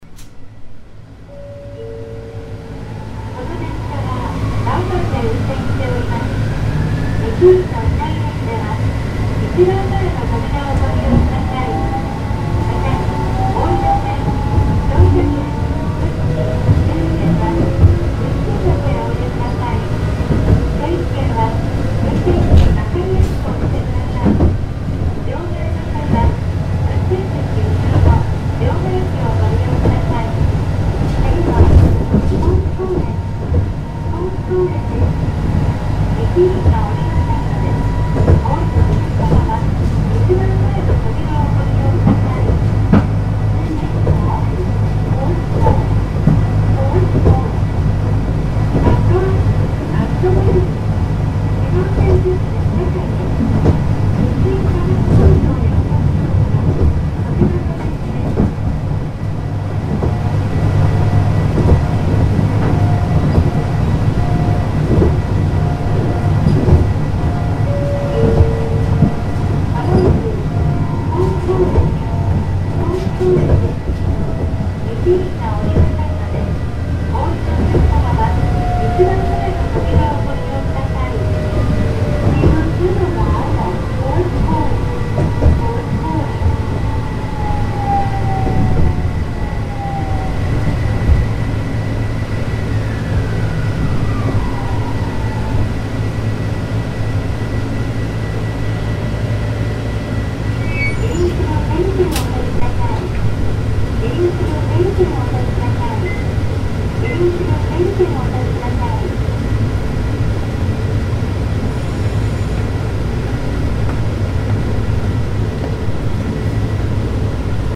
福井鉄道の車両（たけふ新駅）
武生新駅から北府駅まで（車内で録音）